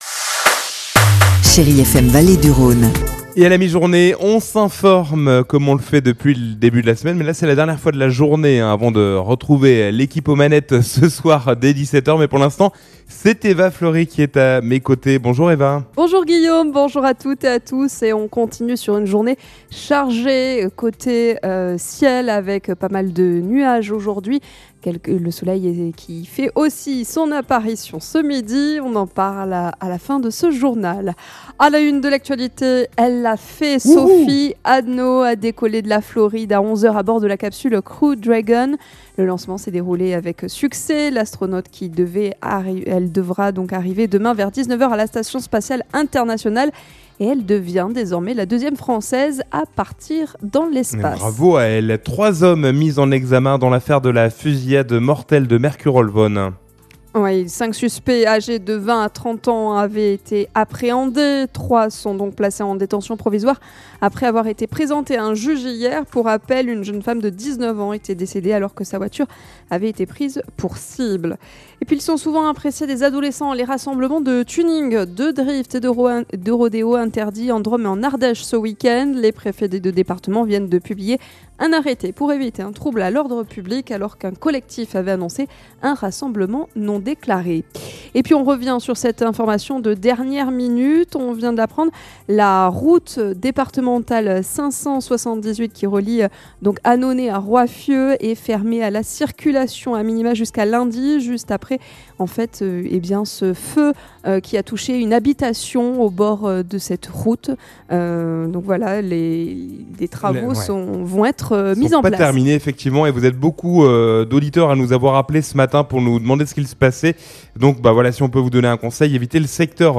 in Journal du Jour - Flash
Vendredi 13 février : Le journal de 12h